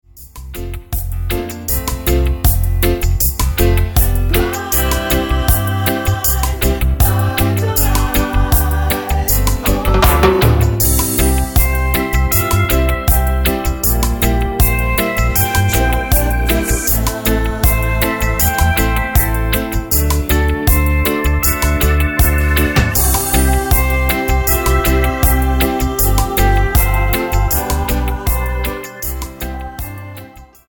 --> MP3 Demo abspielen...
Tonart:C mit Chor
Die besten Playbacks Instrumentals und Karaoke Versionen .